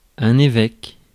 Ääntäminen
Vaihtoehtoiset kirjoitusmuodot (vanhentunut) byshop Synonyymit ordinary hierarch consecrator diocesan bishop suffragan bishop Ääntäminen : IPA : [ˈbɪ.ʃəp] US : IPA : [ˈbɪ.ʃəp] Lyhenteet ja supistumat Bp.